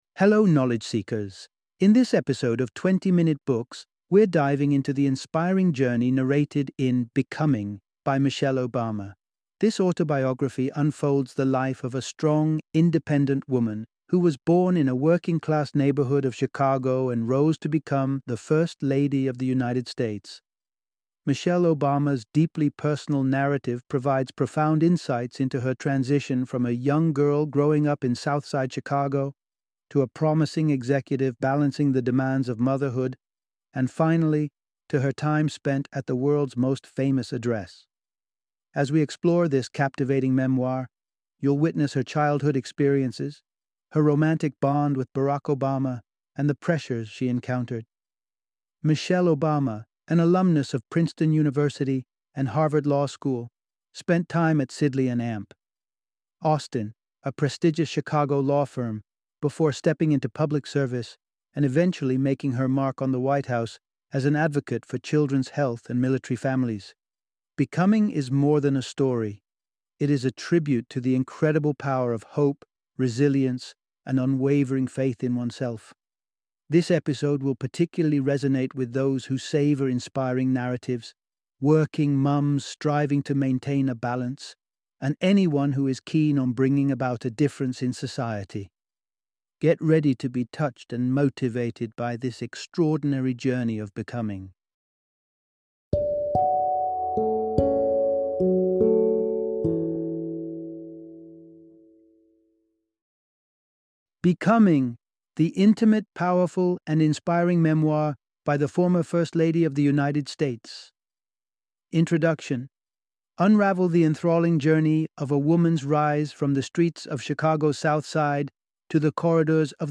Becoming - Audiobook Summary